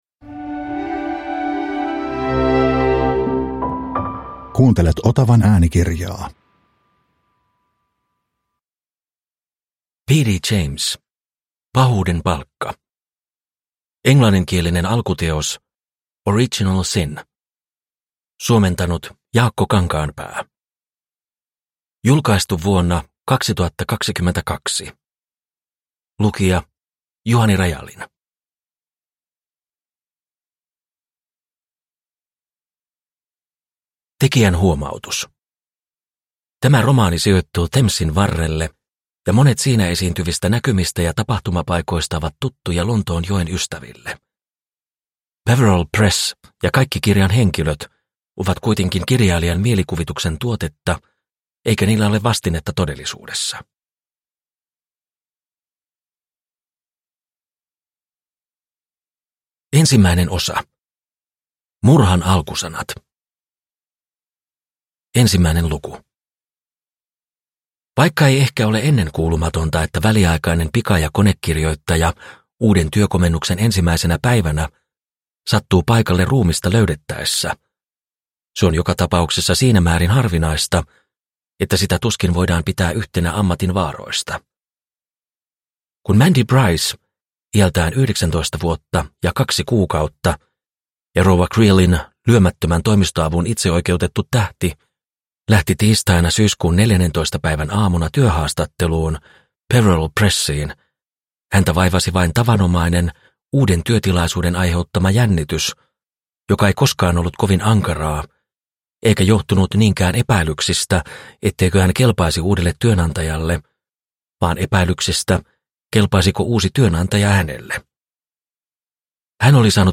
Pahuuden palkka – Ljudbok – Laddas ner